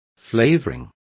Complete with pronunciation of the translation of flavourings.